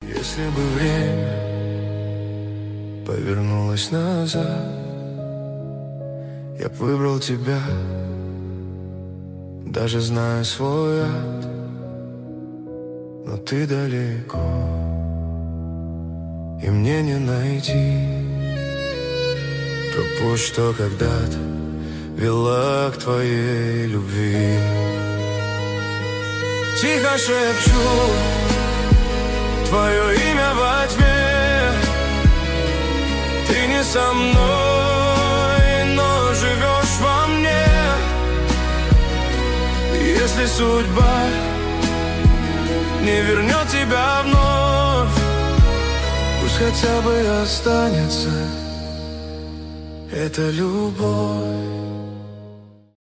грустные , поп
романтические